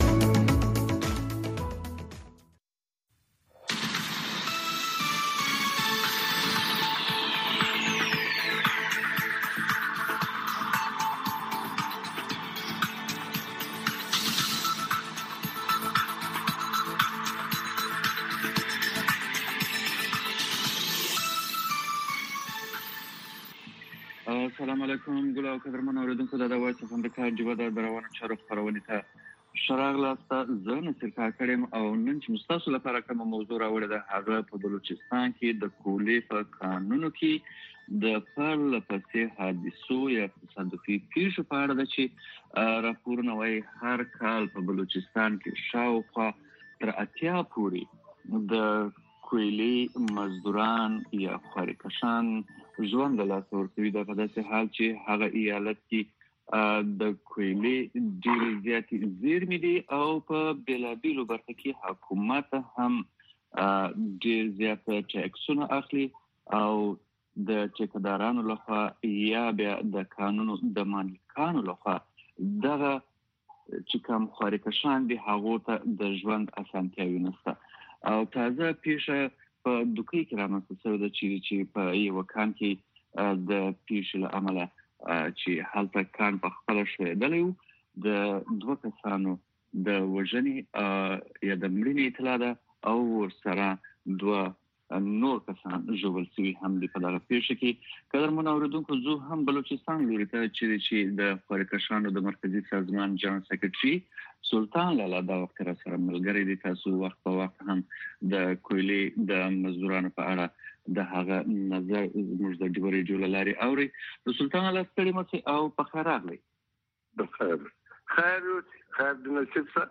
په دې خپرونه کې اوریدونکي کولی شي خپل شعر یا کوم پیغام نورو سره شریک کړي. د شپې ناوخته دا پروگرام د سټرو اوریدنکو لپاره ښائسته خبرې او سندرې هم لري.